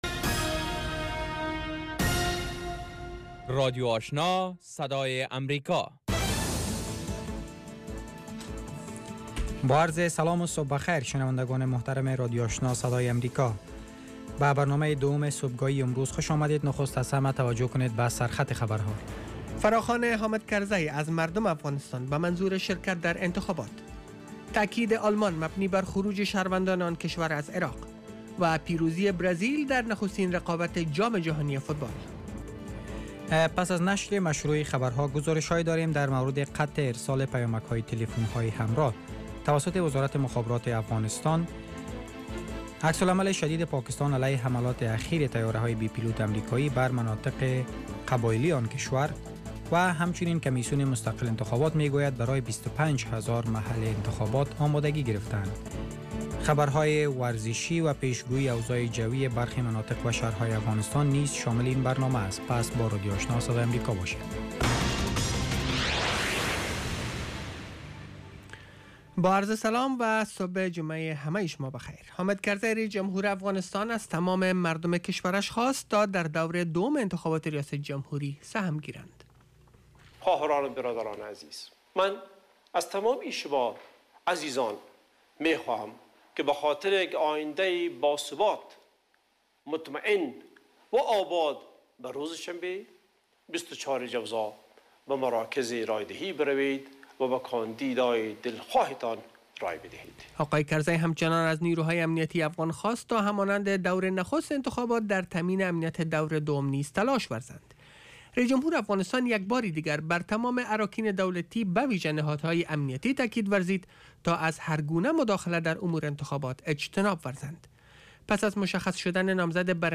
دومین برنامه خبری صبح
morning news show second part